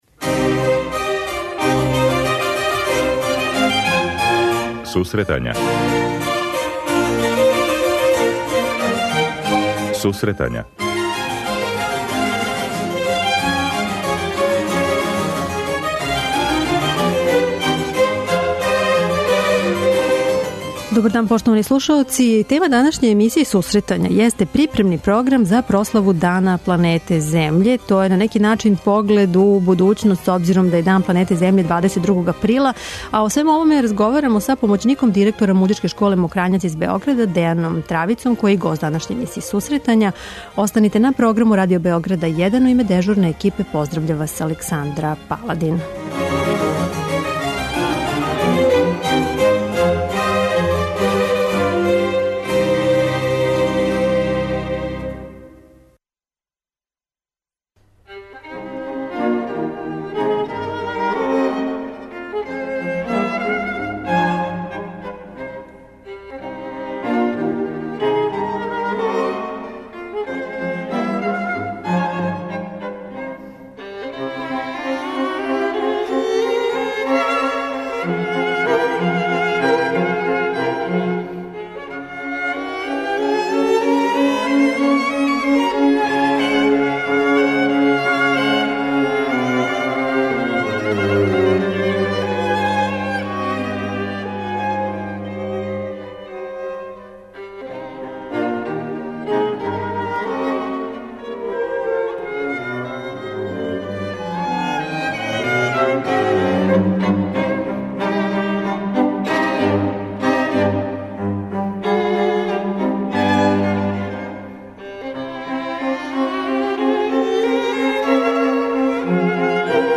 преузми : 27.11 MB Сусретања Autor: Музичка редакција Емисија за оне који воле уметничку музику.